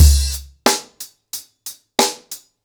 TheStakeHouse-90BPM.19.wav